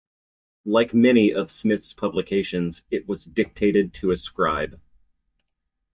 Pronounced as (IPA) /ˌdɪkˈteɪtɪd/